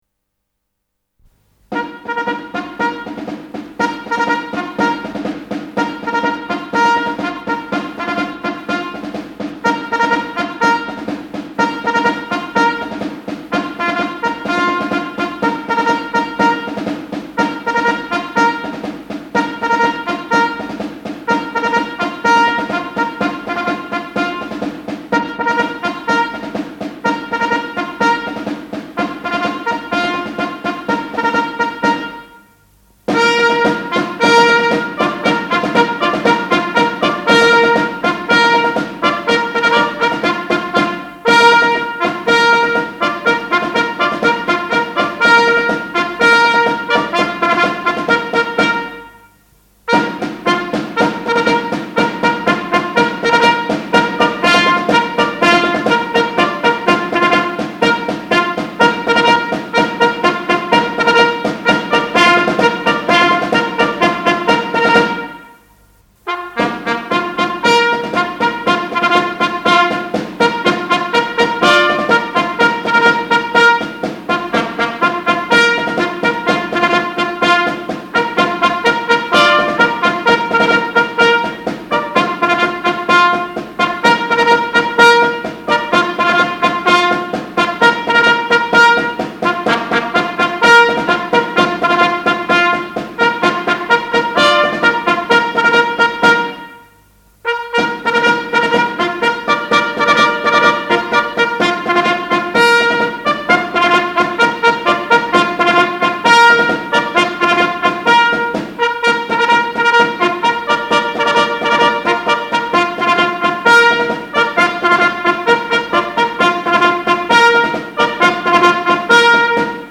Под ЭТО раньше маршировали на пионерских сборах.